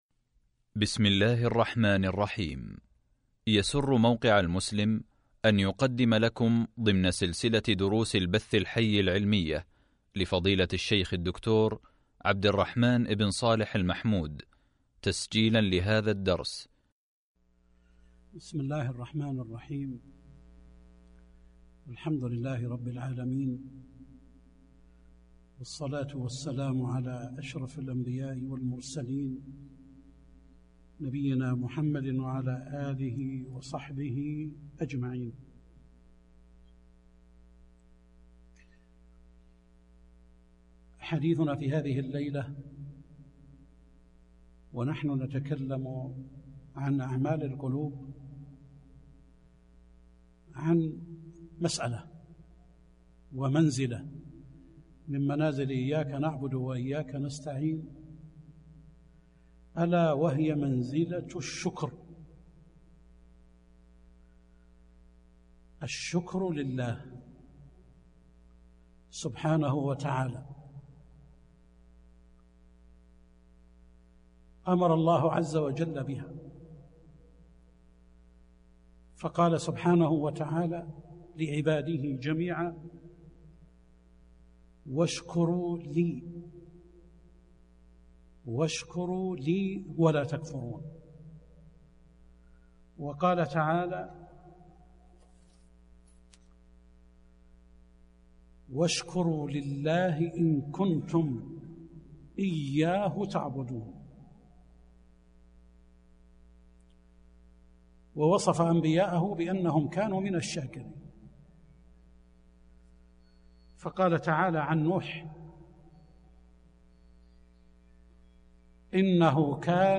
الدرس الثاني عشر من أعمال القلوب (الشكر) | موقع المسلم